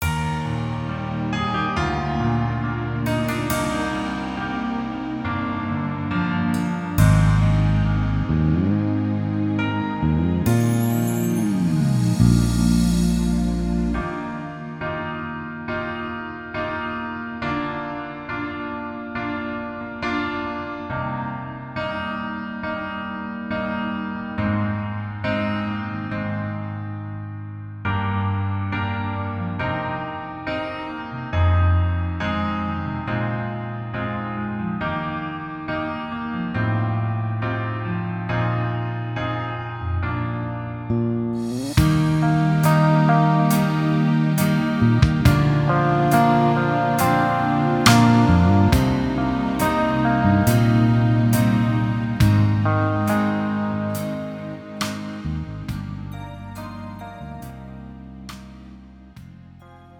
미리듣기
음정 -1키
장르 가요 구분 Pro MR